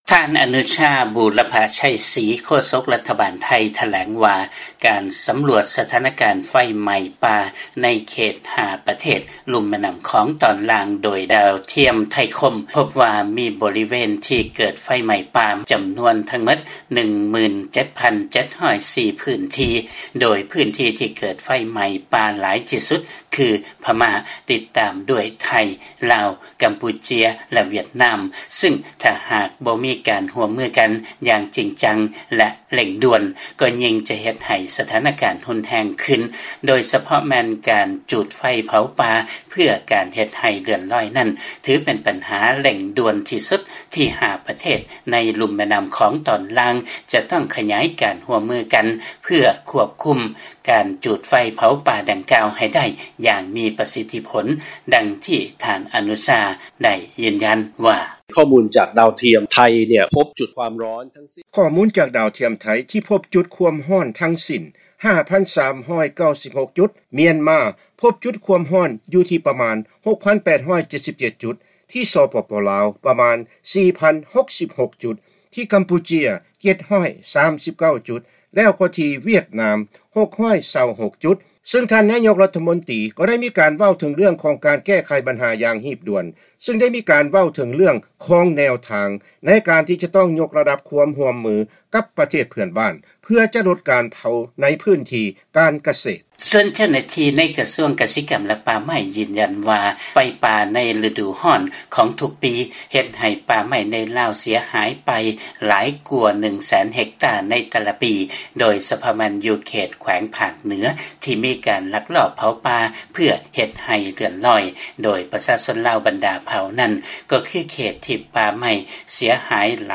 ມີລາຍງານຈາກບາງກອກ